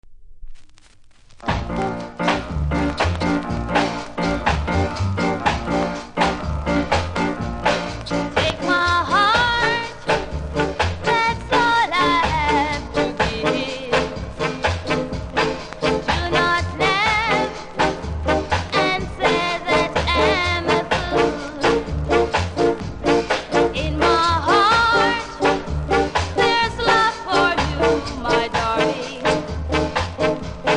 キズは多めですが音は良いので試聴で確認下さい。